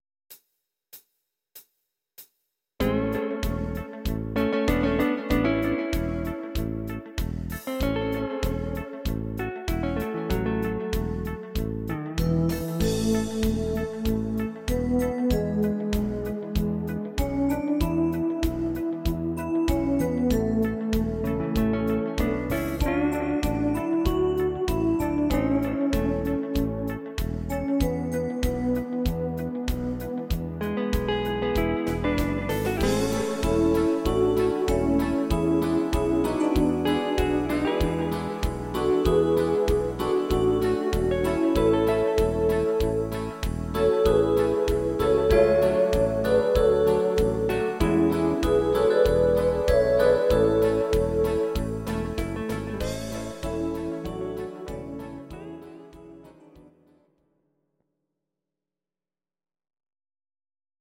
Audio Recordings based on Midi-files
Country, 1980s